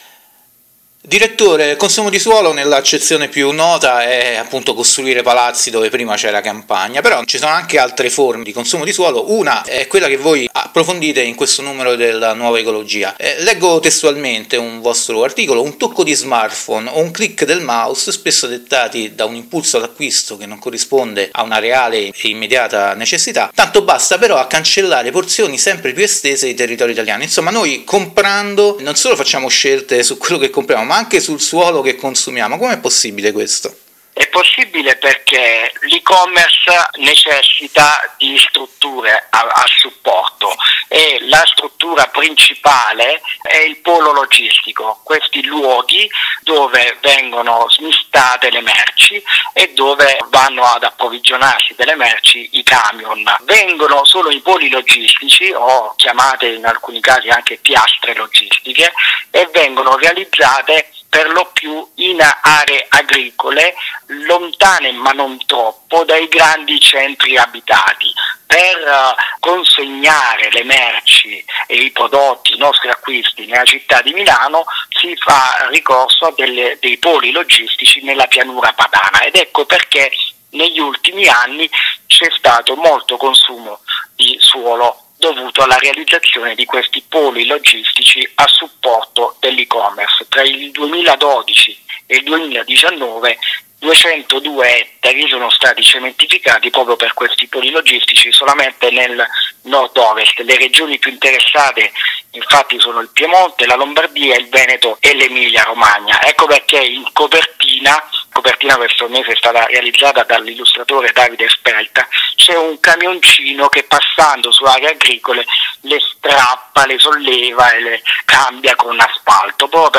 Ecosistema Interviste Pianeta